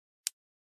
Звуки курицы, Minecraft
В подборке есть клоктанье, испуганные крики и другие характерные звуки этого моба. Отличное качество аудио без посторонних шумов.